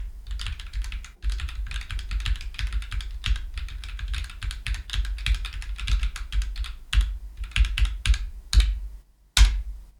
※マイクゲインはmax100の状態です。
1：ノイズゲートON状態（デフォ設定）
2：キーボードからマイクまで約30cm